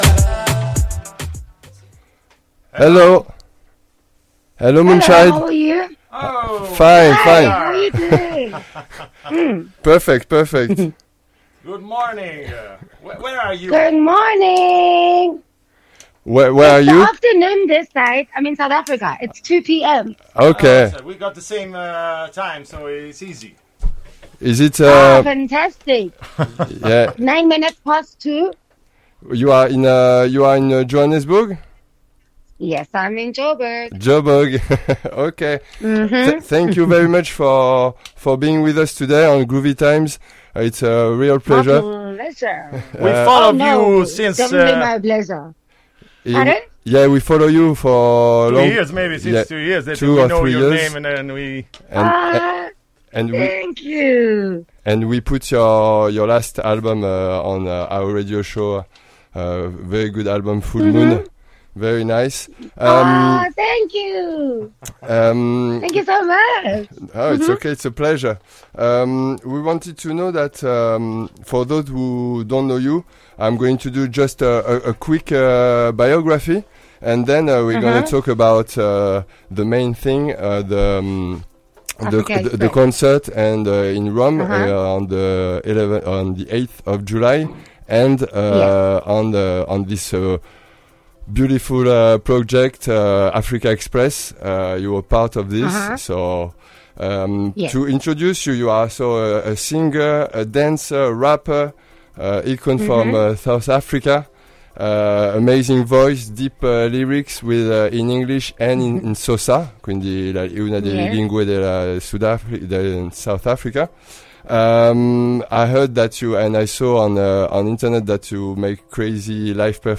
Intervista a Moonchild Sanelly | Radio Città Aperta